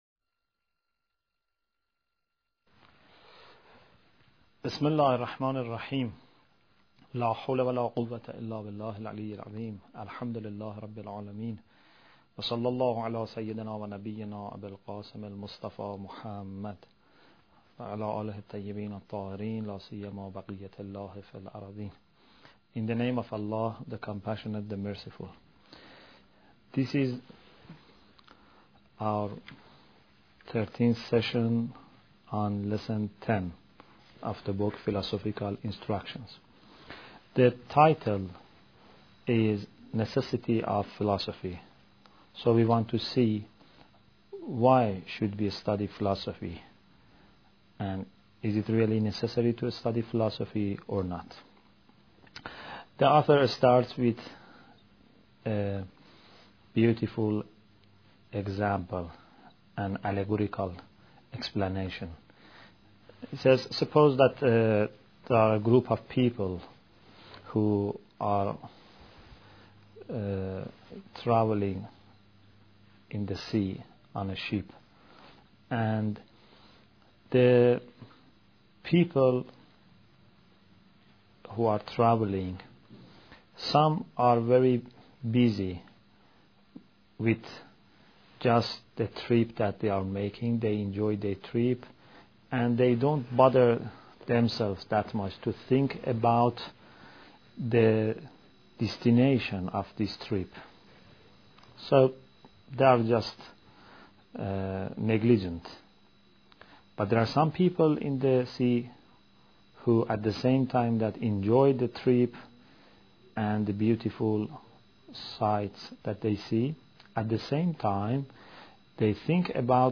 Bidayat Al Hikmah Lecture 13